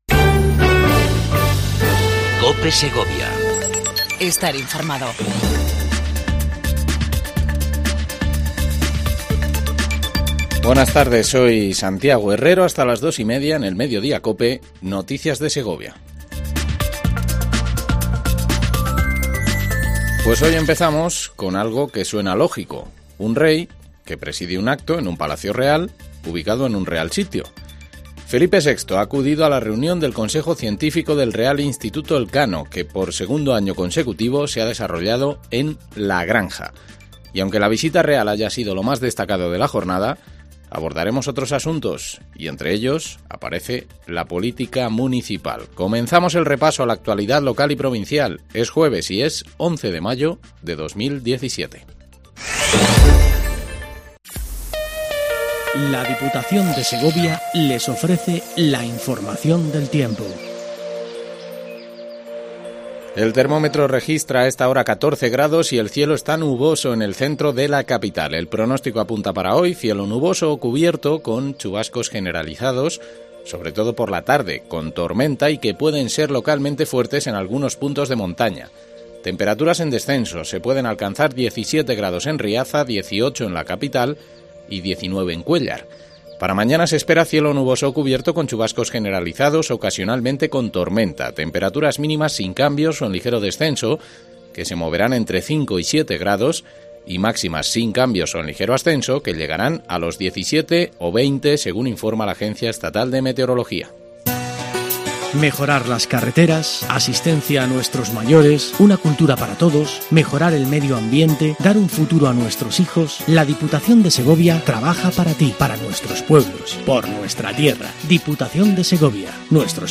INFORMATIVO MEDIODIA COPE EN SEGOVIA 11 05 17